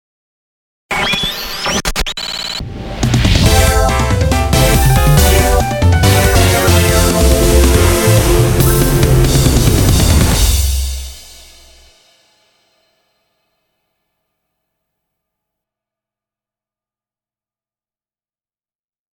大昔に友人に依頼されて制作した、ラジオ番組用のジングル。
賑やか・やかましさの中に爽やかな雰囲気。